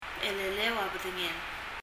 何度聴いても 最初の /b/ が聴こえません [? élɛlɛ (ə) b(ə)ðŋɛl] と聴こえます